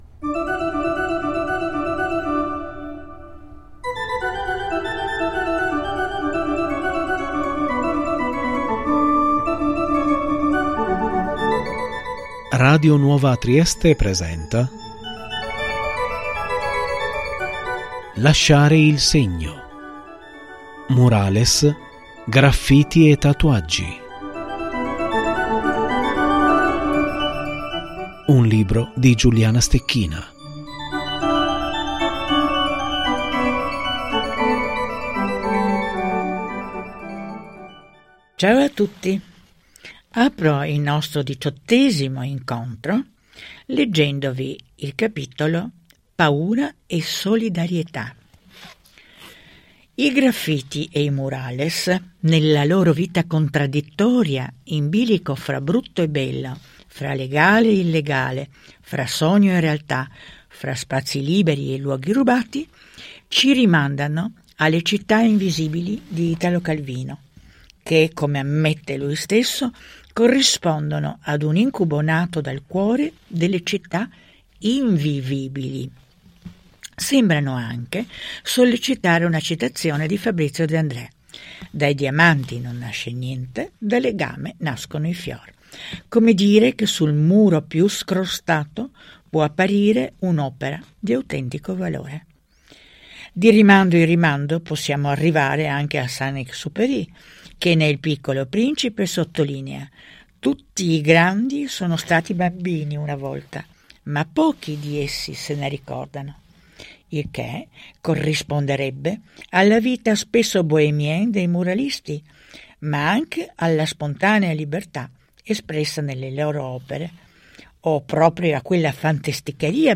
Lettura d’Autore – “Lasciare il segno” – 6 novembre 2024